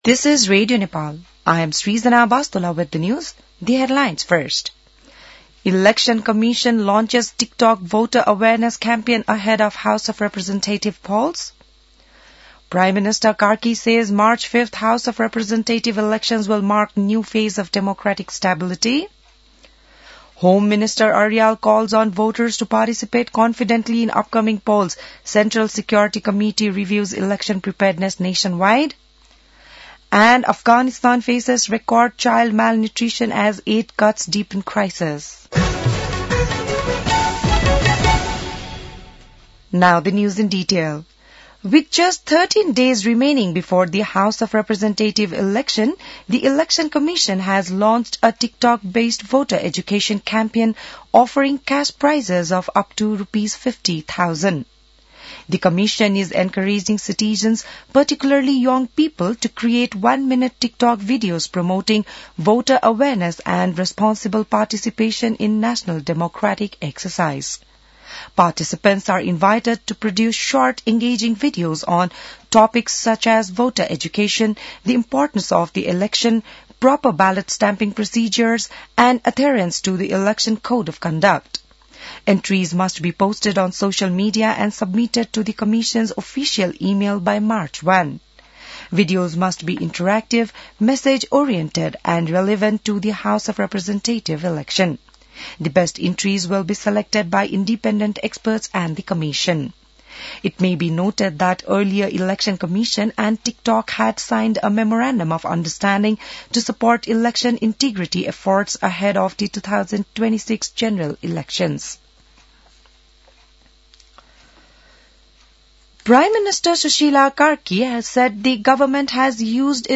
बिहान ८ बजेको अङ्ग्रेजी समाचार : ८ फागुन , २०८२